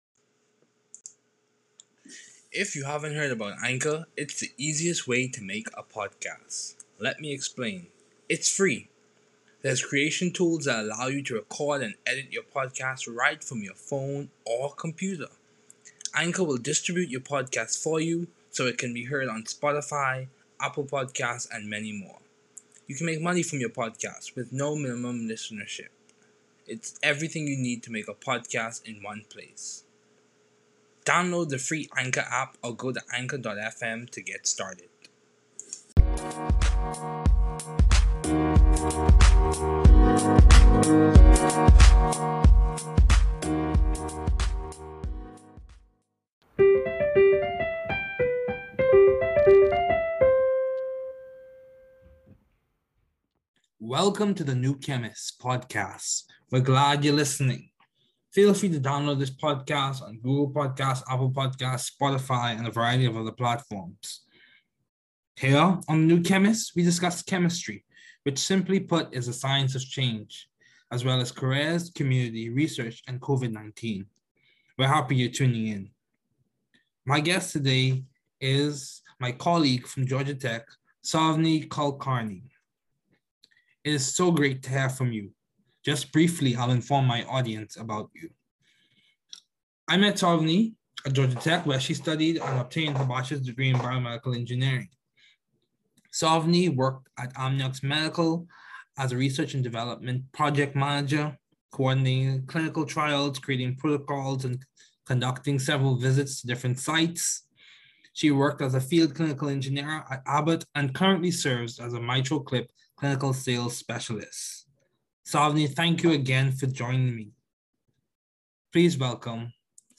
In this episode this successful student, now a specialist at Abbot, discussed her career thus far, and what has helped her achieve her academic success to date. An excellent interview.